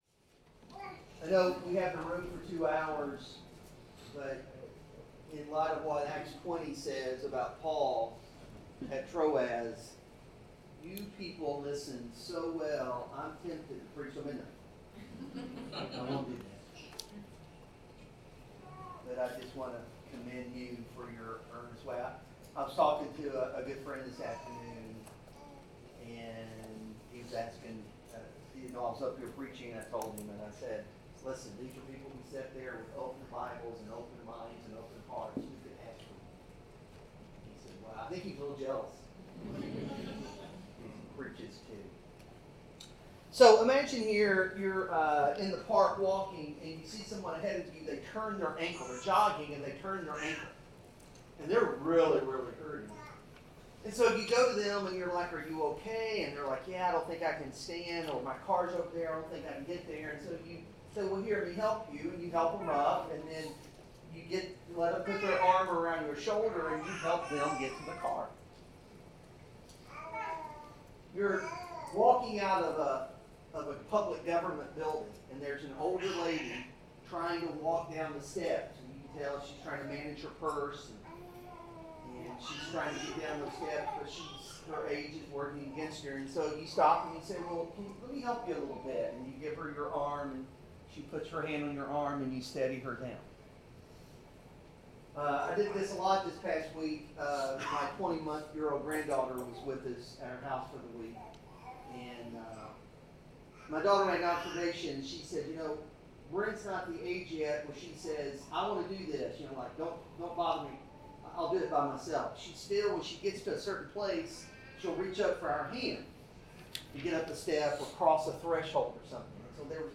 Series: Grow Conference 2026 Service Type: Sermon